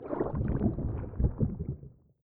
walkslosh3.ogg